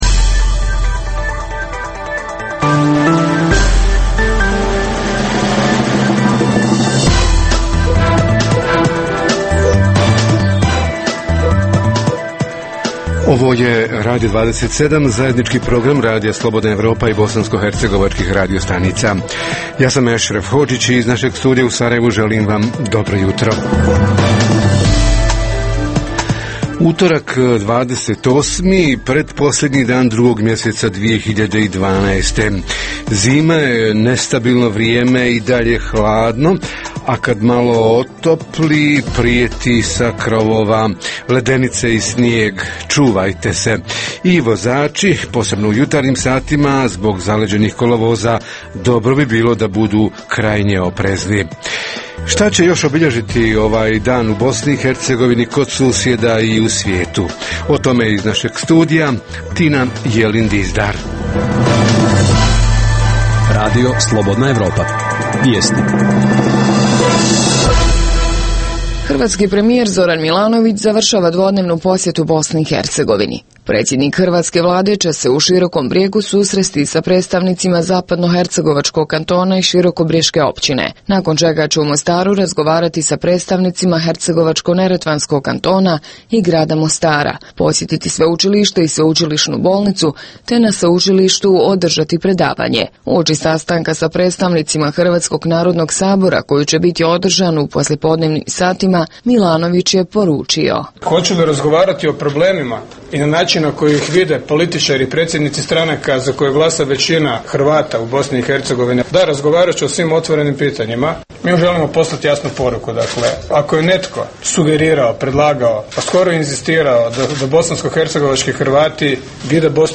Tema jutarnjeg programa: proizvodnja i prerada mlijeka – kako da proizvođači i prerađivači u BiH zadovolje domaće potrebe i više izvoze – kako se izboriti sa sve jačom konkurencijom iz susjednih zemalja? Reporteri iz cijele BiH javljaju o najaktuelnijim događajima u njihovim sredinama.
Redovni sadržaji jutarnjeg programa za BiH su i vijesti i muzika.